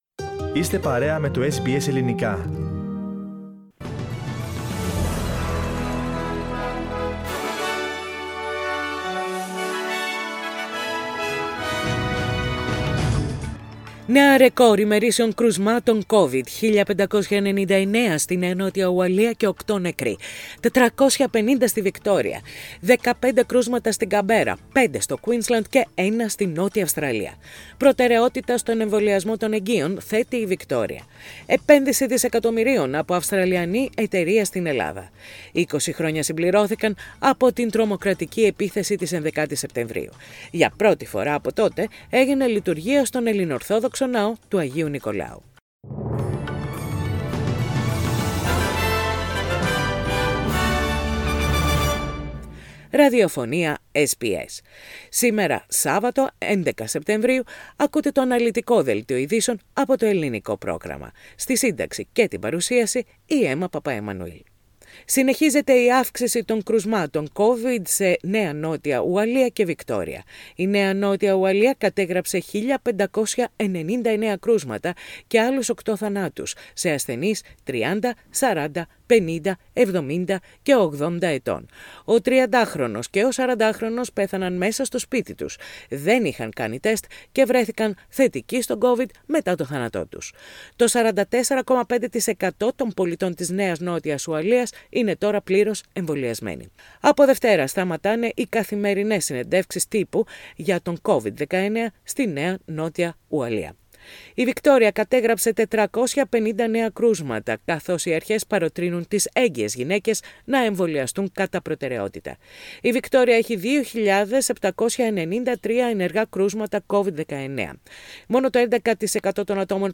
News in Greek - Saturday 11.9.21